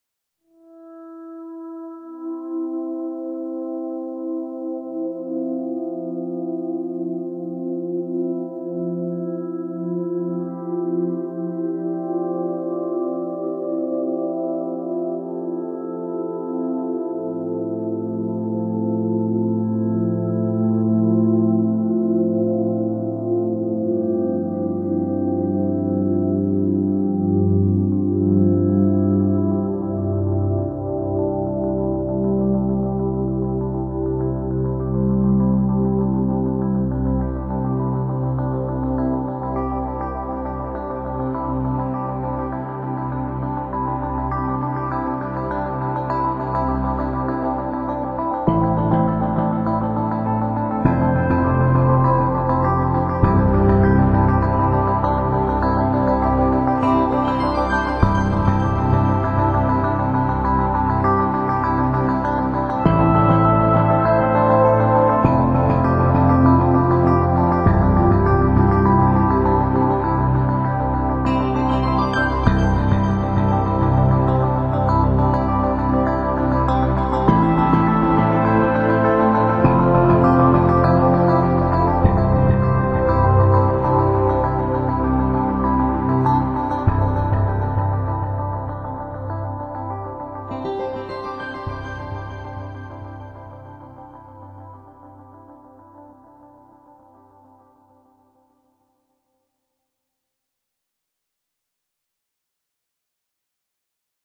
主题鲜明的钢琴演奏专辑，琢磨出钢琴静谧、激情的双面美感
雷霆般的低音鼓、雨滴似的风铃声，当快速的钢琴音阶登场，可以想像这场雨下得有多大，而躲在云层背后的弯月静静地操纵一切；